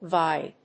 音節vi・de 発音記号・読み方
/vάɪdi(米国英語)/